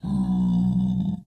mob / zombiepig / zpig1.ogg